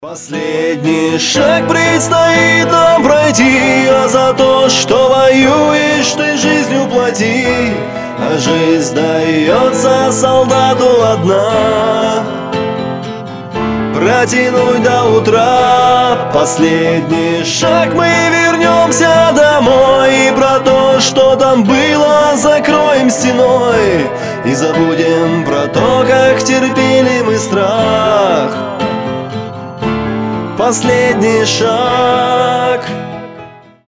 • Качество: 320, Stereo
душевные
грустные
армейские
фолк-рок